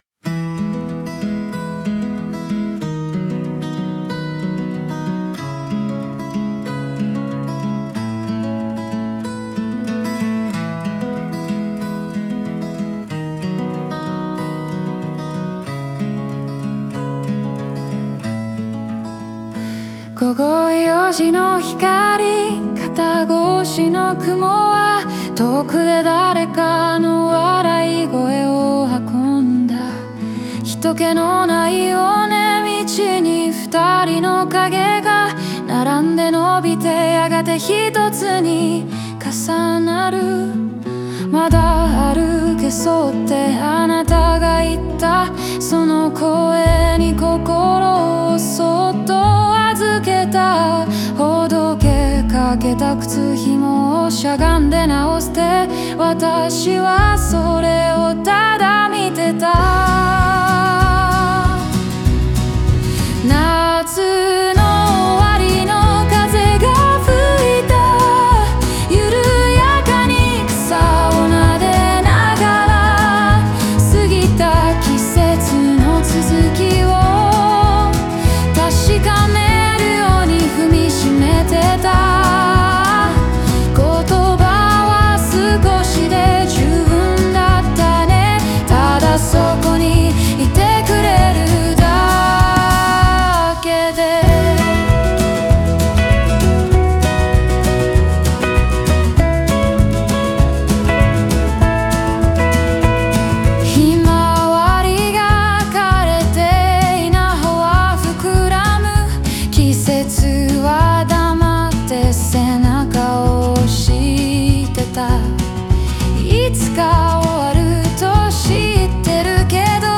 草を撫でる風や虫の声といった自然の音が織り込まれ、空気感や季節の移ろいを繊細に演出。